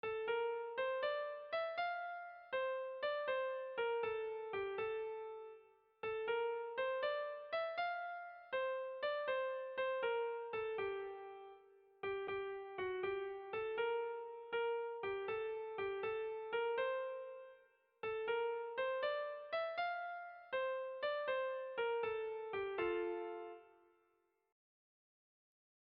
Kontakizunezkoa
Zortziko txikia (hg) / Lau puntuko txikia (ip)
A-A-B-C